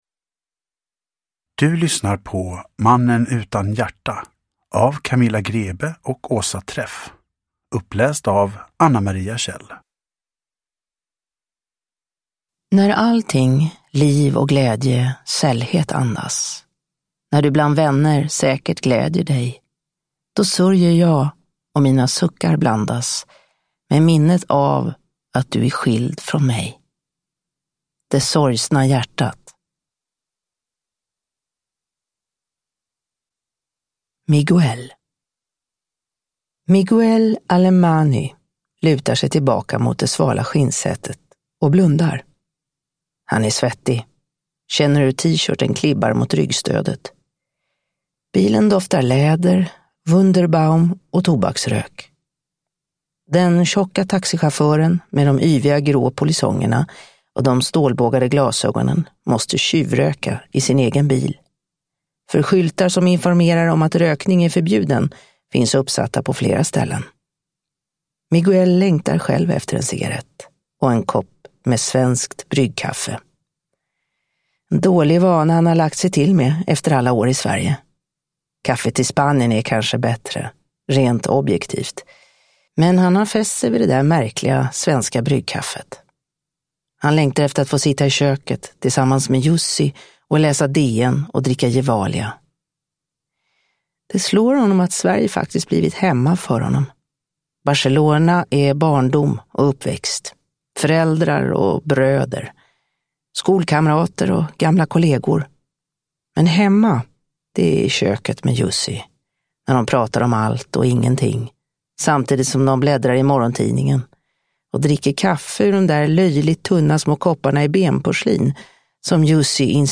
Mannen utan hjärta – Ljudbok – Laddas ner